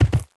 fire_tiger.wav